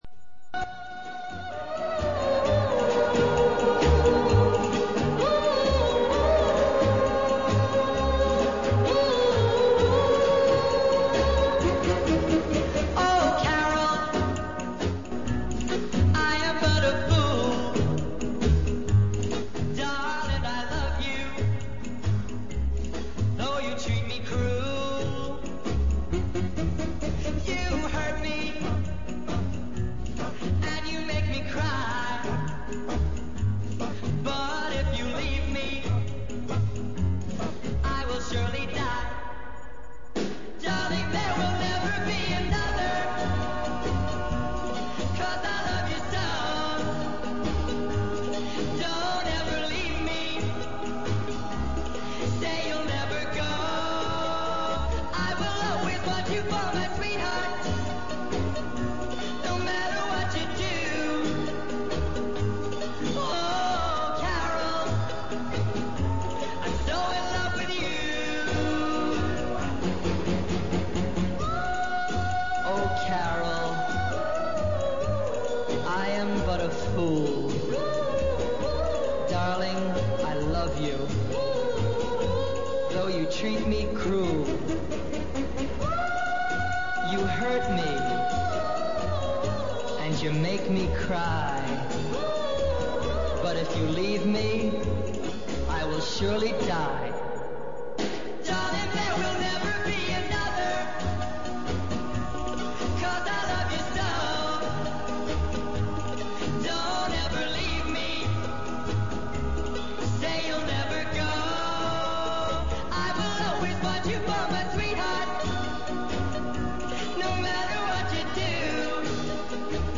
Anoche tuvimos como invitado a este dj, productor y agitador musical y cultural que, entre otras muchas cosas, es miembro del colectivo Fresh Flesh y uno de los activistas del interesante blog Rebited . Vino a hablarnos de sus múltiples proyectos y, ya de paso, ayudarnos a iniciar el camino en ese complejo mundo del hip hop instrumental, la electrónica y demás estilos desconocidos para nosotros y que a él, como demuestra en su faceta de blogger, tanto le apasionan.